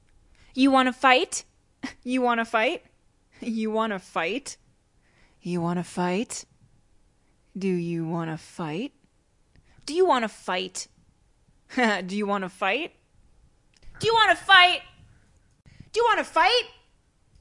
视频游戏中的女声 " 你最好快跑
标签： 讲话 谈话 声音 女孩 性感 英语 女性 讲话 美国 声乐 女人 视频游戏
声道立体声